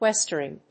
/wéstərɪŋ(米国英語)/
westering.mp3